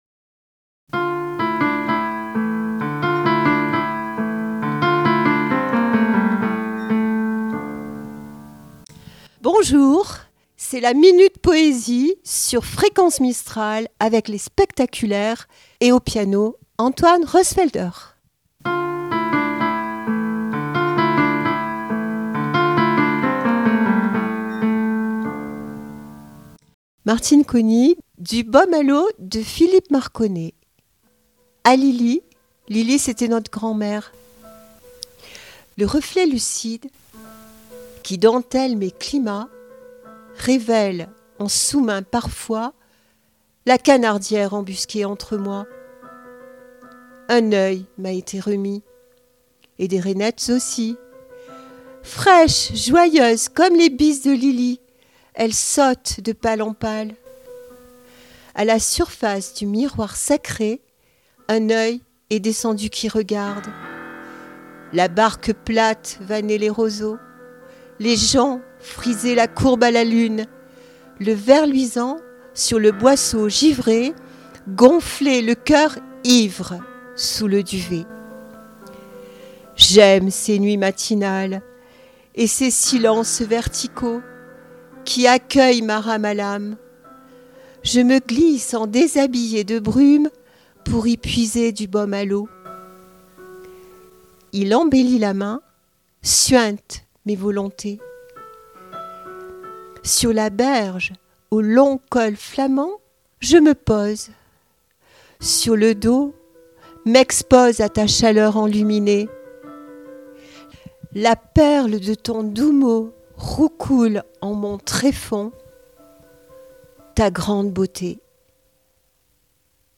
La poésie se murmure au rythme du piano sur Fréquence Mistral
Poésie- 1 Avril.mp3 (4.88 Mo) Chronique poésie sur Fréquence Mistral avec : "les Spectaculaires", une troupe amateur qui fait s’envoler les mots au son du piano, une parenthèse sonore où les mots dansent , entre poésie et émotion